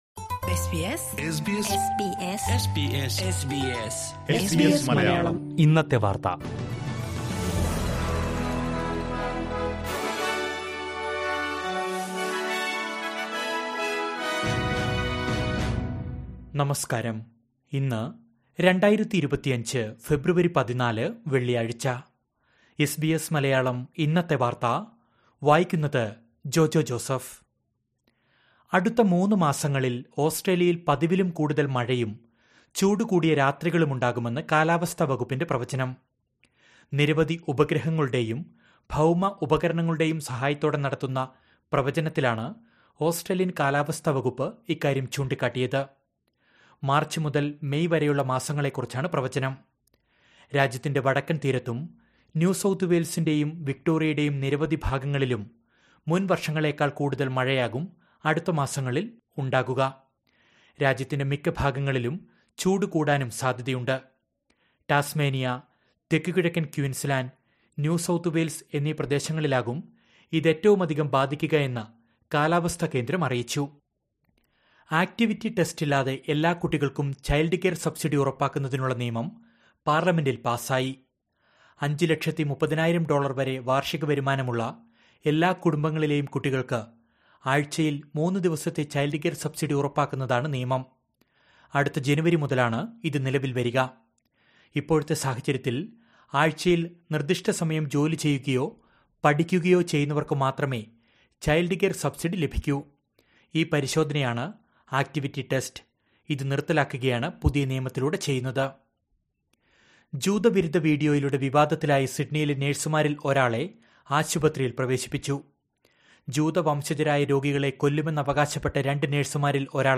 2025 ഫെബ്രുവരി 14ലെ ഓസ്‌ട്രേലിയയിലെ ഏറ്റവും പ്രധാന വാര്‍ത്തകള്‍ കേള്‍ക്കാം...